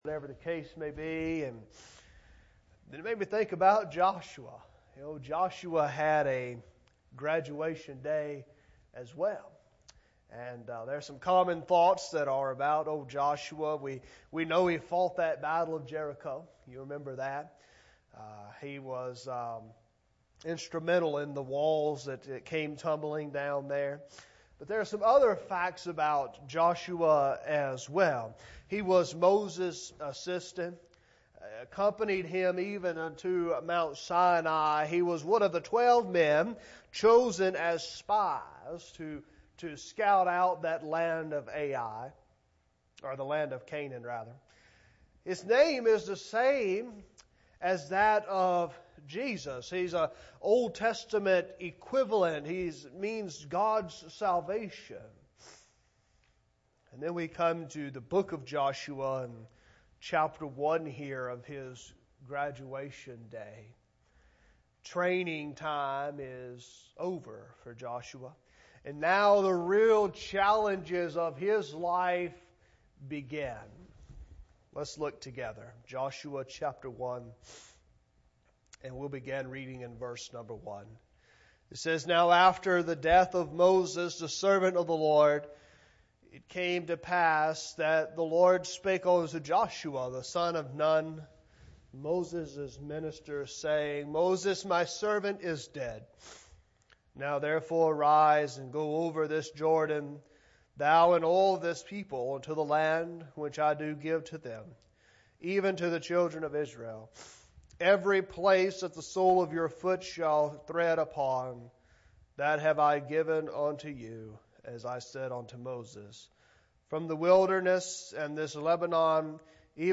Sermons | West Acres Baptist Church
Guest Speaker